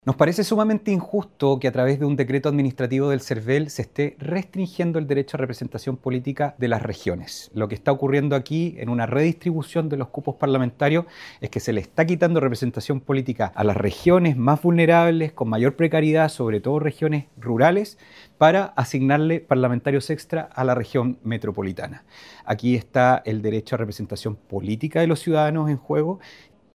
El diputado Ricardo Neumann, fue uno de los que manifestó su inconformidad con la noticia pues considera que es más centralismo, escuchemos: